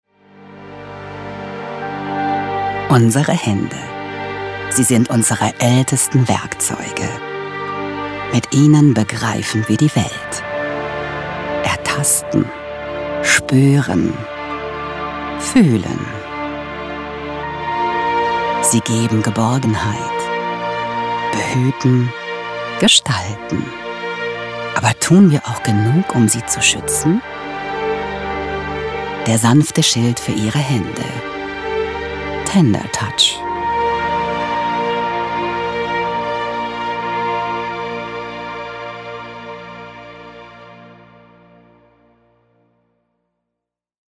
Charakterstimme, erregt Aufmerksamkeit, samtig-tief, einfĂŒhlsam, innovativ, glaubhaft, hohe Wiedererkennung, TV-Werbung, Audioguide, Doku / Off, E-Learning, Feature, Spiele, Funkspot, Imagefilm, Meditation, Nachrichten, ErklĂ€rfilm, Promotion, Sachtext, Trailer, Station-Voice, VoiceOver, Tutorials
Sprechprobe: Werbung (Muttersprache):
Character voice, attracts attention, velvety-deep, sensitive, innovative, credible, high recognition, TV commercial, audio guide, documentary / off-camera, e-learning, feature, games, radio spot, image film, meditation, news, explanatory film, promotion, factual text, trailer, station voice, voiceover, tutorials